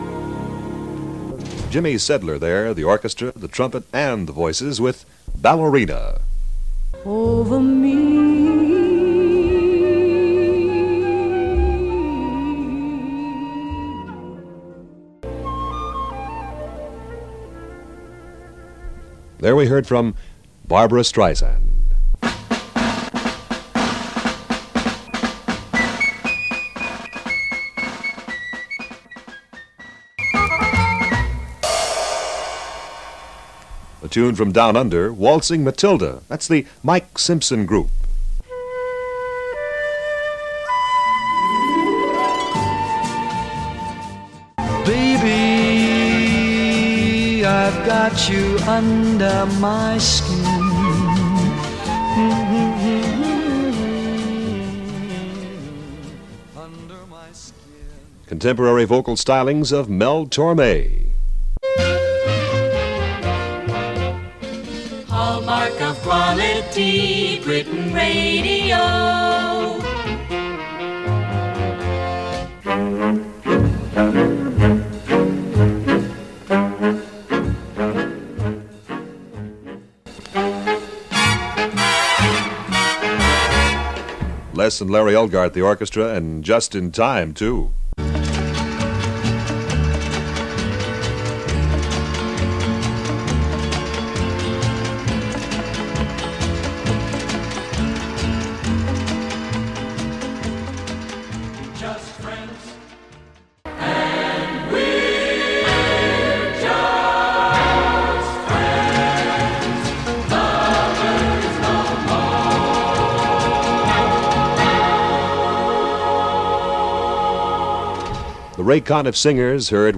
That station was equipped with an automated playout system which was able to insert adverts, jingles and voice links into tapes of pre-recorded music. The system was used overnight and occasionally during the day if the weather was particularly bad.
programme on Britain Radio